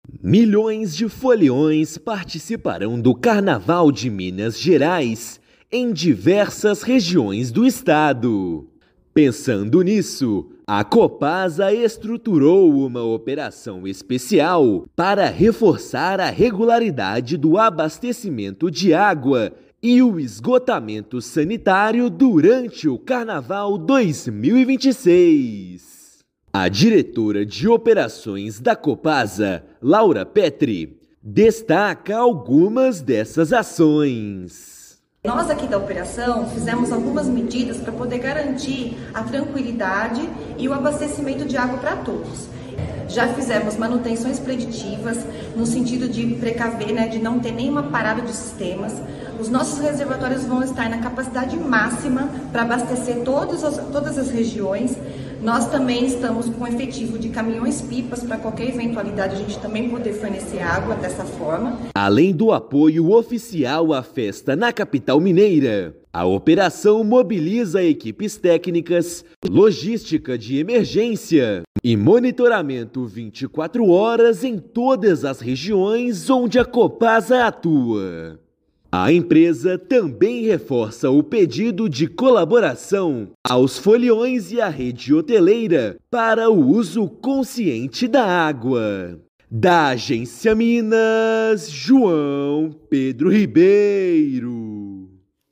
Diante do aumento da demanda, principalmente em cidades históricas e polos turísticos, a companhia iniciou um plano de contingência. Ouça matéria de rádio.